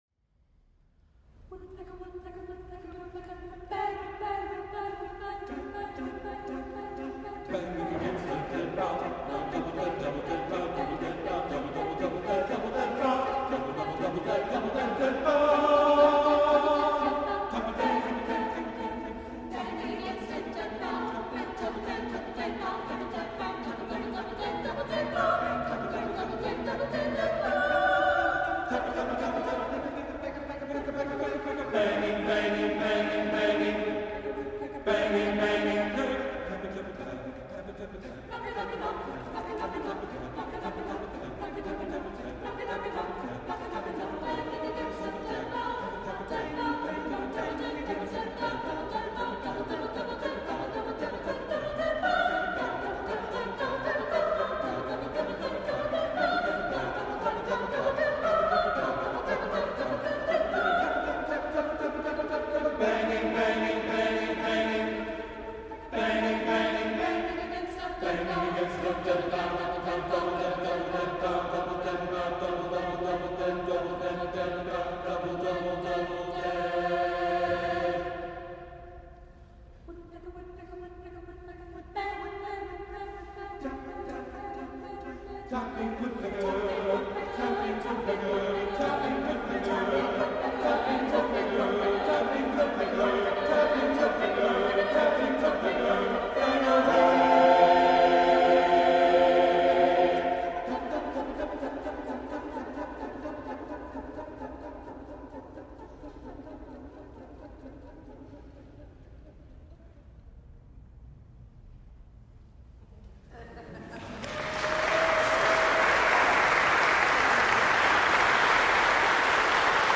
Full Performance